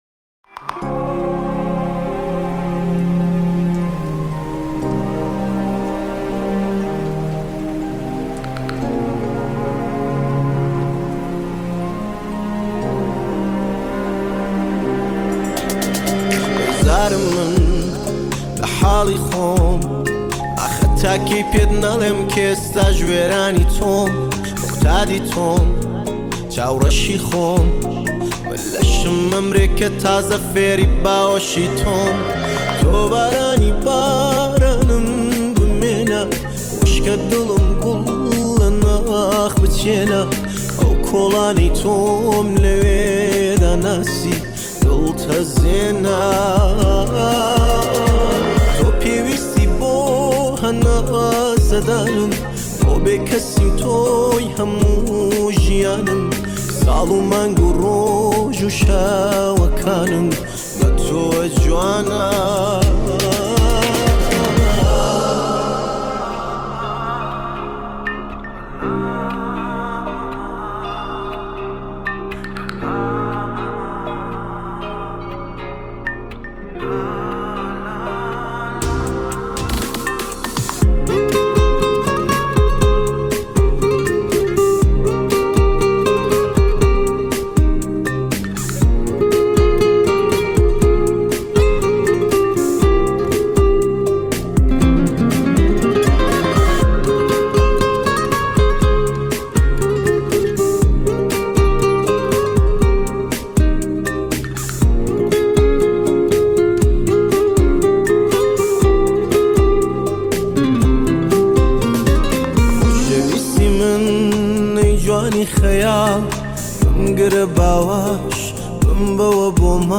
آهنگ کوردی
آهنگ با صدای زن